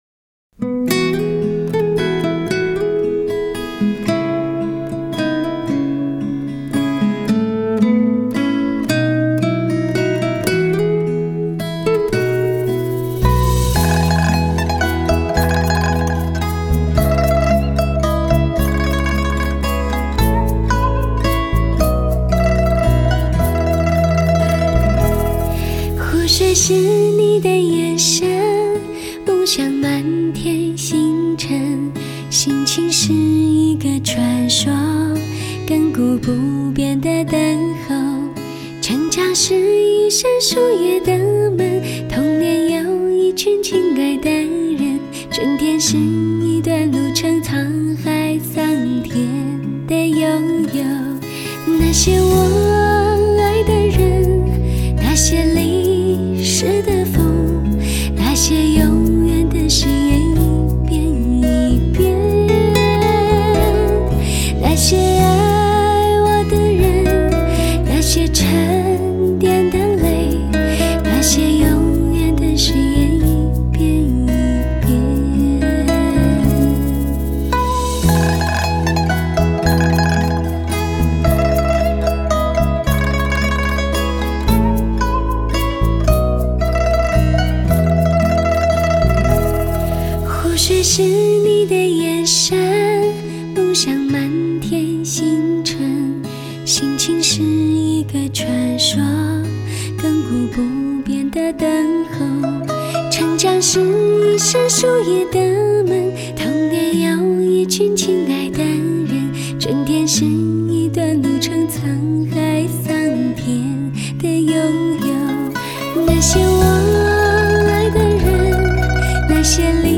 人声柔润顺滑 吉他浪漫激情
声音清 结像活 动态强